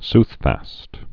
(sthfăst)